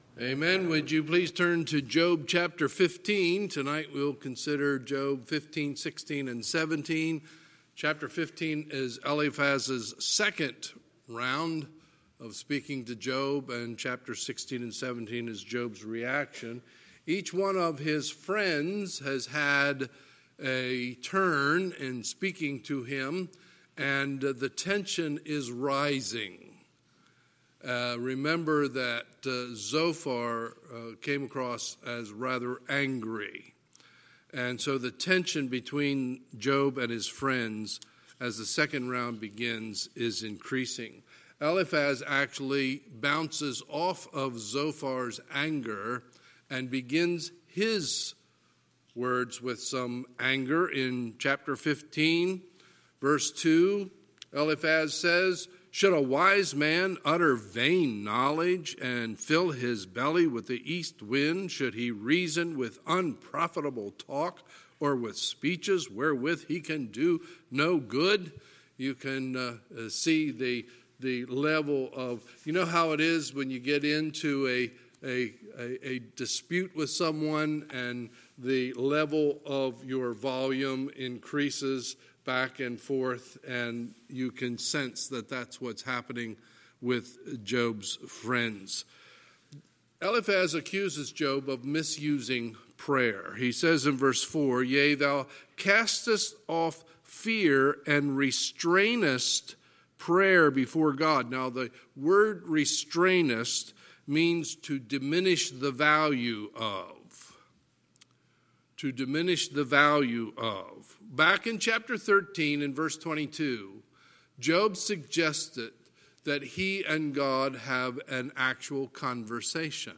Sunday, February 16, 2020 – Sunday Evening Service